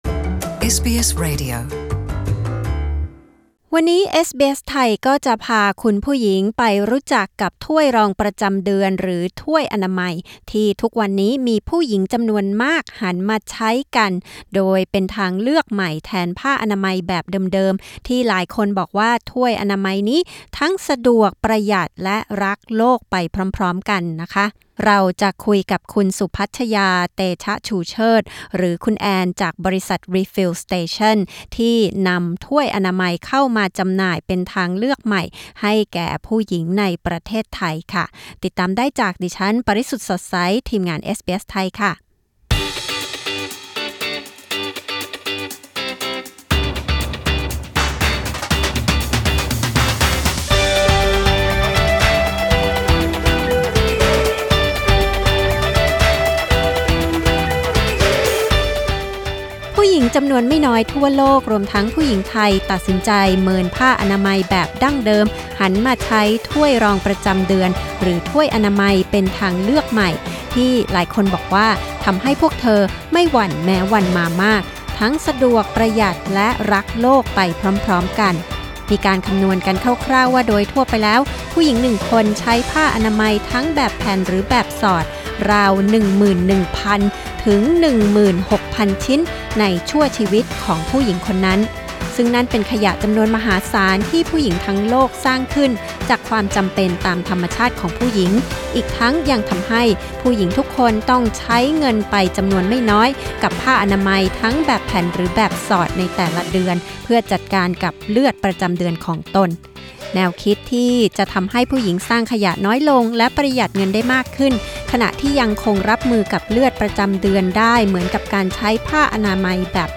กดปุ่ม 🔊 ด้านบนเพื่อฟังสัมภาษณ์เรื่องถ้วยอนามัยอย่างละเอียด